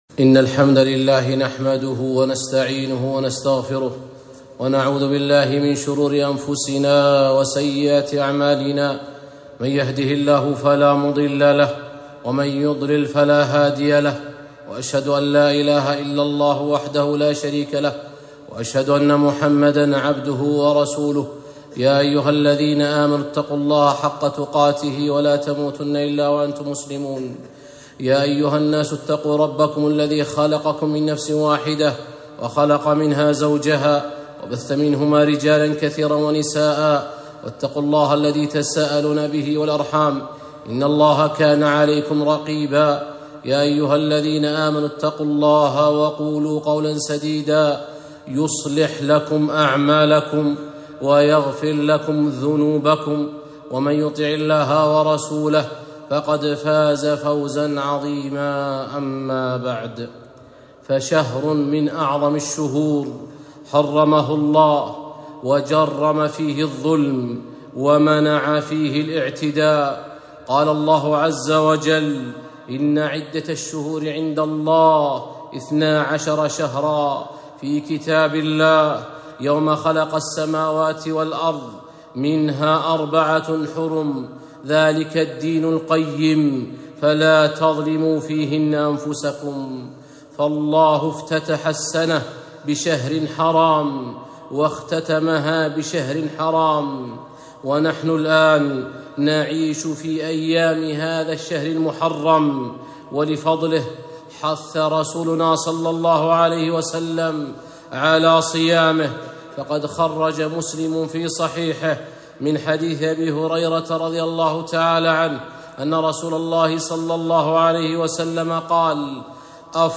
خطبة - اليوم العاشر